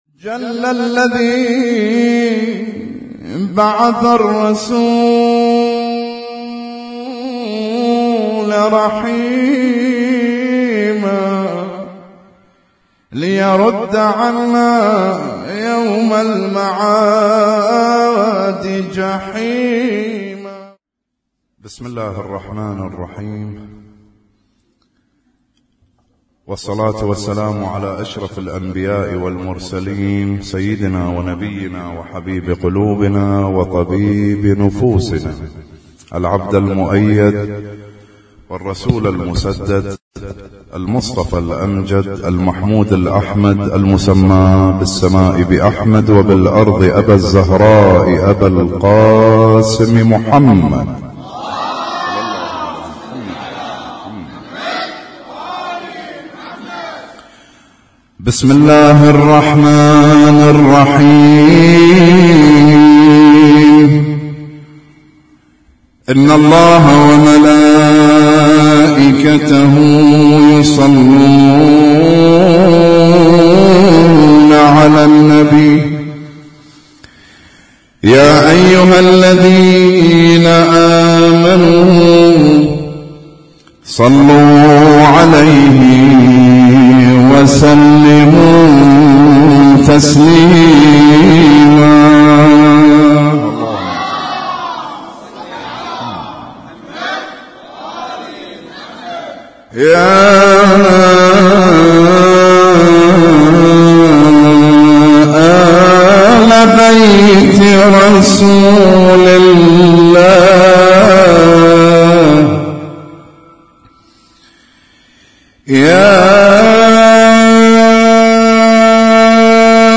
اسم التصنيف: المـكتبة الصــوتيه >> المواليد >> المواليد 1436